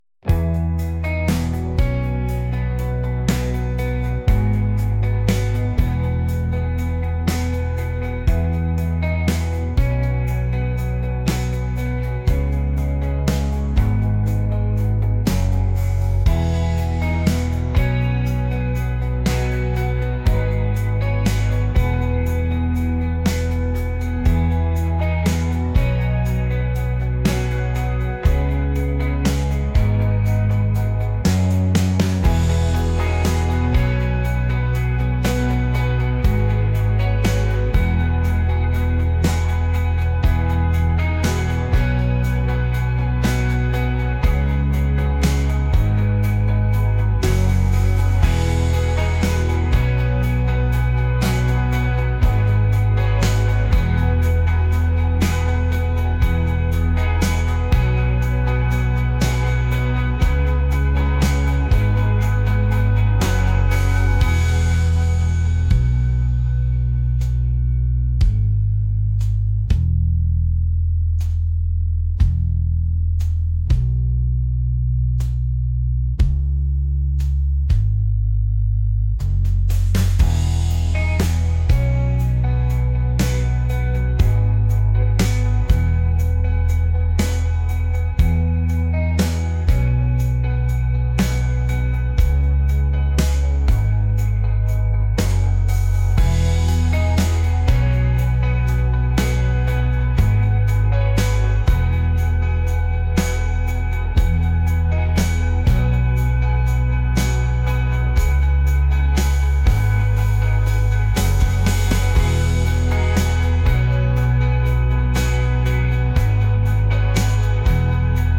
indie | acoustic | laid-back